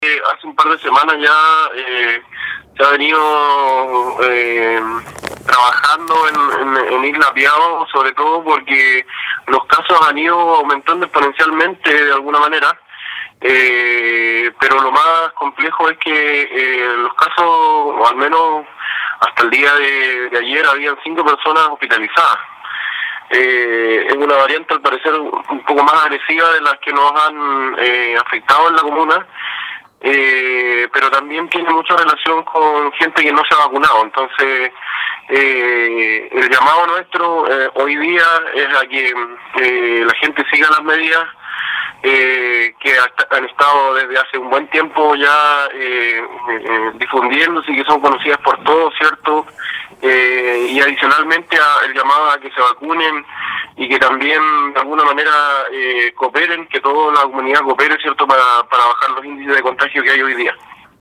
El alcalde (suplente) de Quinchao, Víctor Pérez, manifestó que cinco personas se mantienen hospitalizadas, en un conjunto de personas que no han querido vacunarse, lo que los ha expuesto más a este brote en esa comunidad.